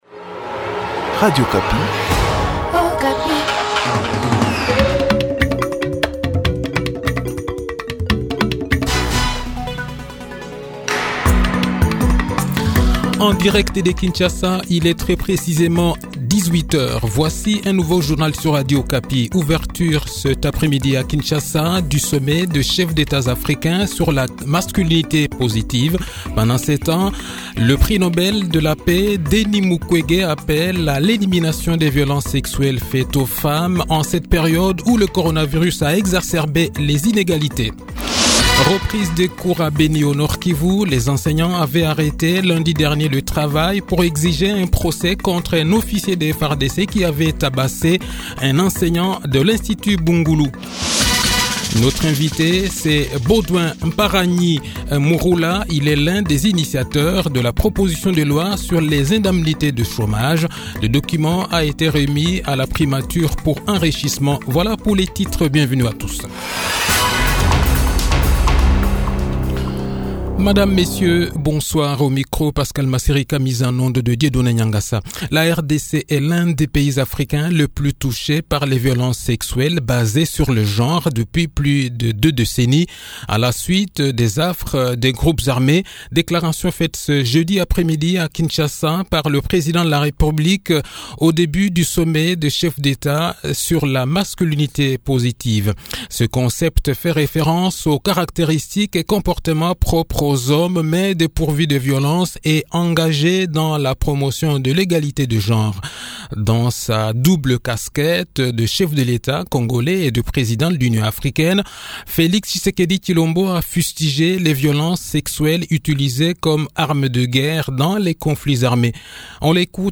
Le journal de 18 h, 25 Novembre 2021